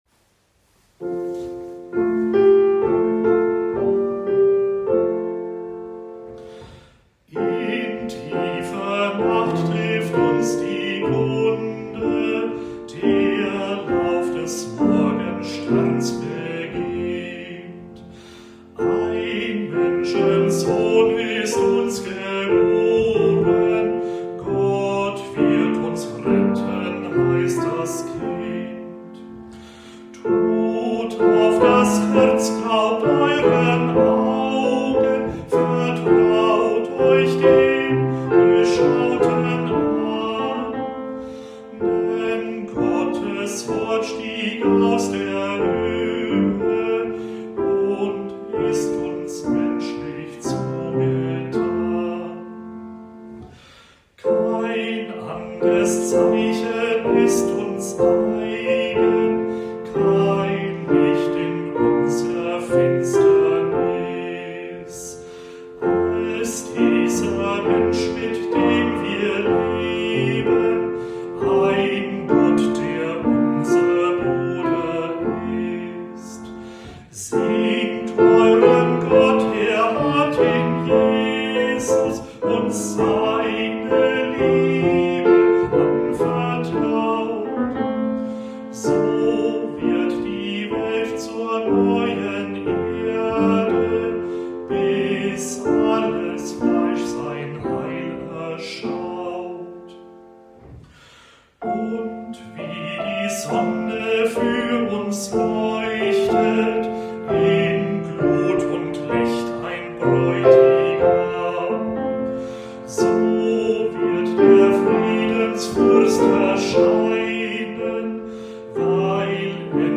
Ein Weihnachtslied zum Kennenlernen